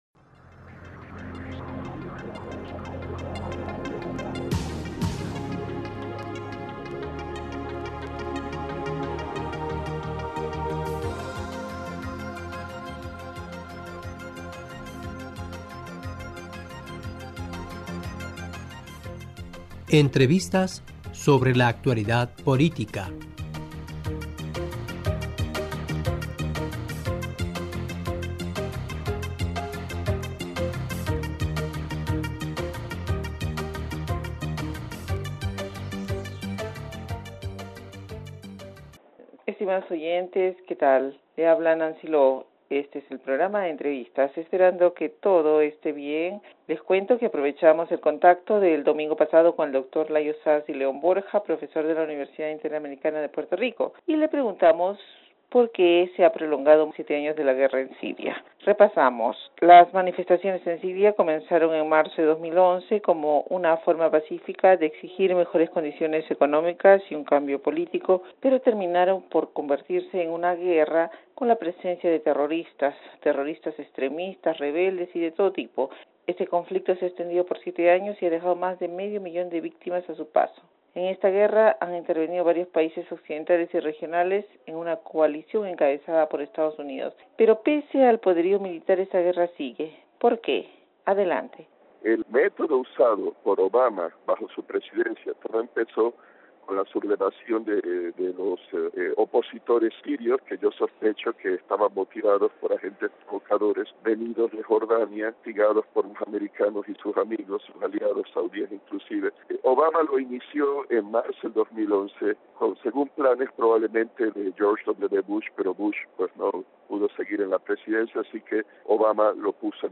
Este es el programa Entrevistas.